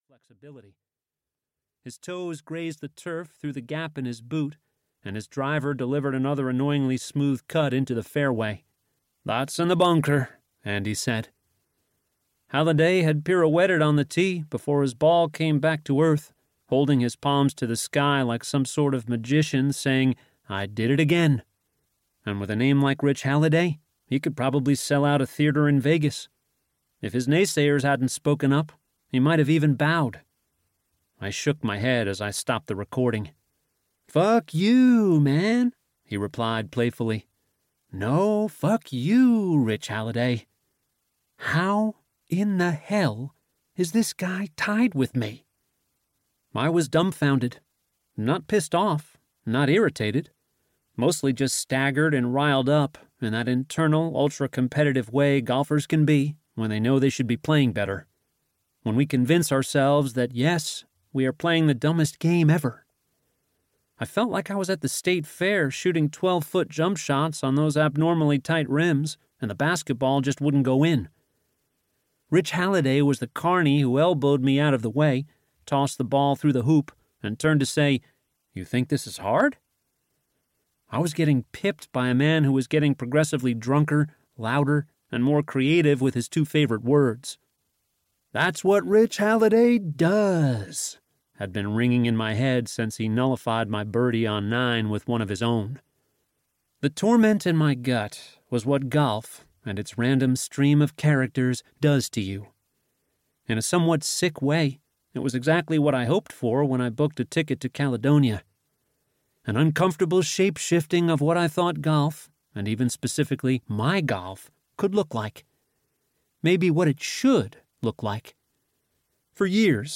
Audiobook Narrator
Nonfiction Samples